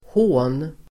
Uttal: [hå:n]